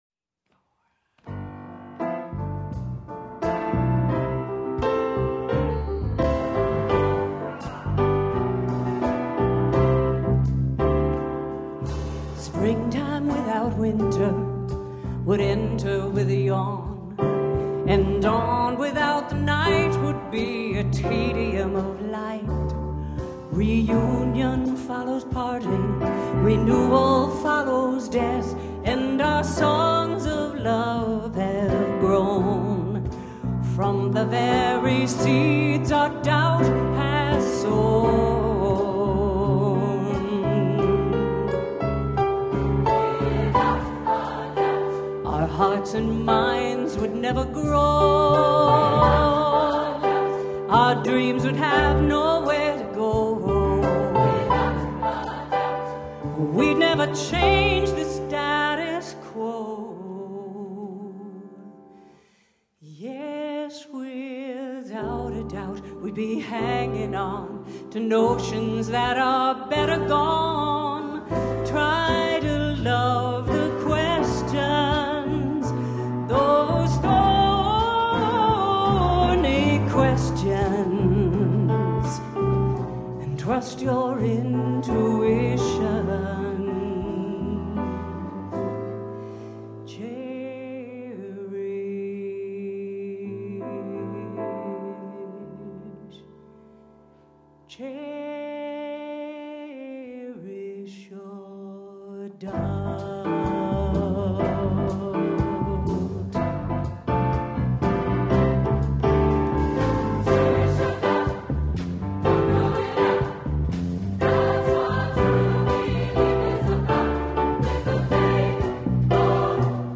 SSAA, soloist, piano, opt. bass